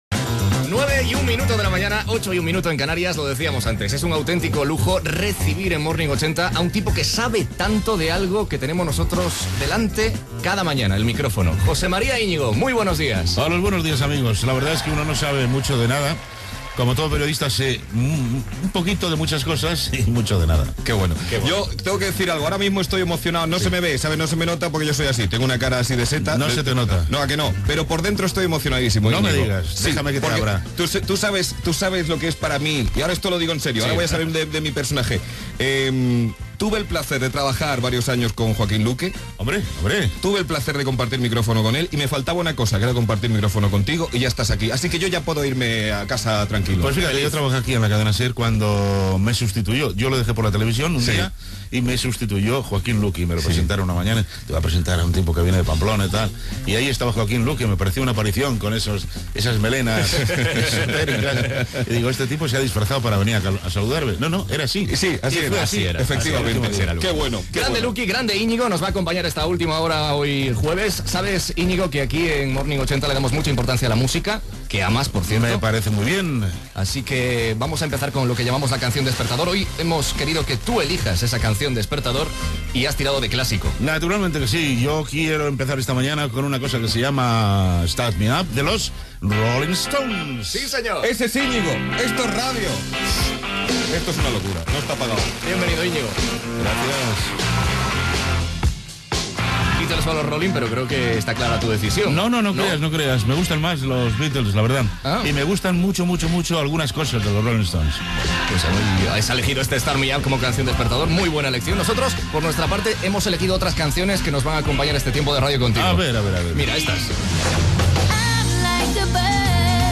Hora, participació al programa del presentador José María Íñigo. "La canción despertador", amb diversos temes musicals, la publicitat de fa anys, "Los telepitos", l'ús de l'iPod que fa Íñigo, indicatiu
Entreteniment